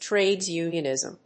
アクセントtráde(s) únionìsm